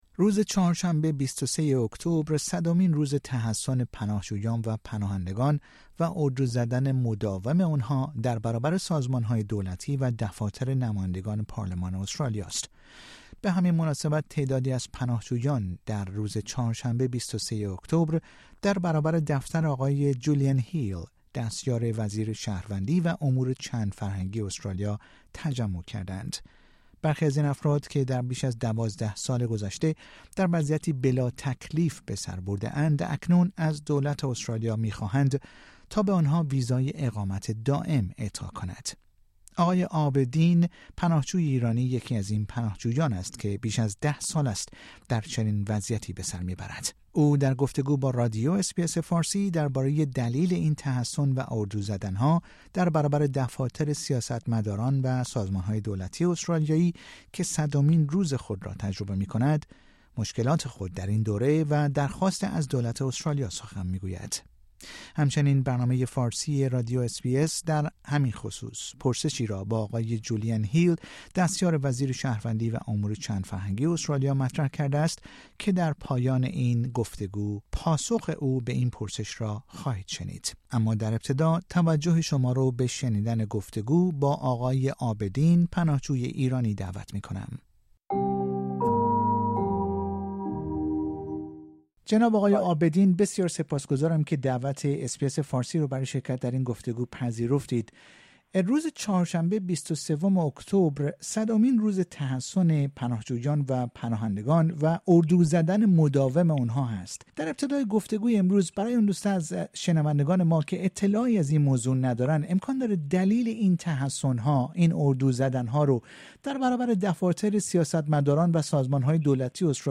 او در گفتگو با رادیو اس بی اس فارسی درباره دلیل این تحصن و اردو زدن ها در برابر دفاتر سیاست مداران و سازمان های دولتی استرالیایی که صدمین روز خود را تجربه می کند، مشکلات خود در این دوره و درخواست از دولت استرالیا سخن می گوید. همچنین برنامه فارسی رادیو اس بی اس در همین خصوص پرسشی را با آقای جولین هیل دستار وزیر شهروندی و امور چندفرهنگی استرالیا مطرح کرده است که در پایان این پادکست، پاسخ او به این پرسش را خواهید شنید.